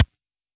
Index of /musicradar/retro-drum-machine-samples/Drums Hits/WEM Copicat
RDM_Copicat_SY1-Snr03.wav